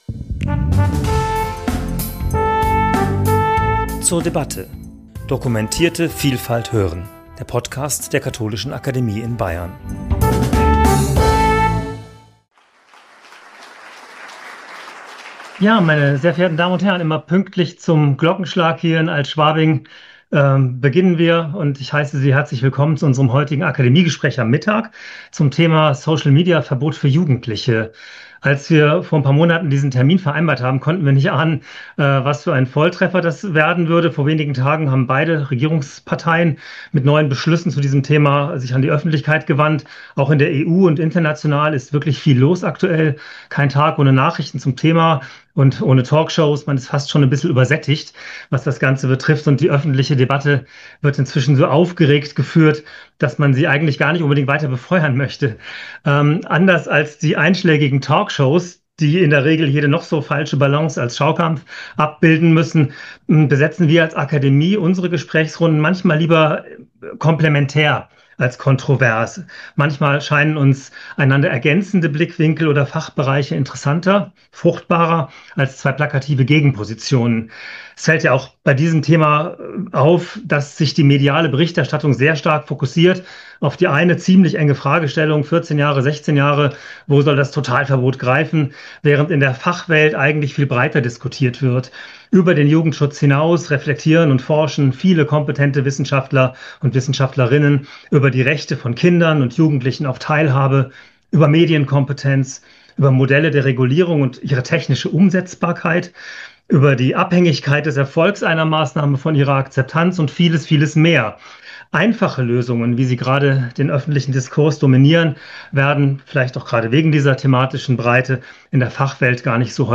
Gespräch zum Thema 'Social-Media-Verbot für Jugendliche?' ~ zur debatte Podcast